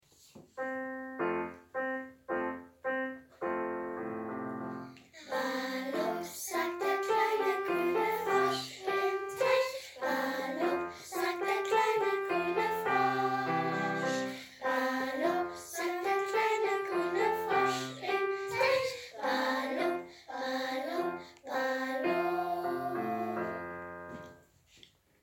FRIDOLIN DER FROSCH - Liedeinspielung Vielen Dank an die Schüler/innen der Josef-Grundschule Würzburg!